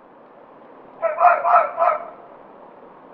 Red-Fox.wav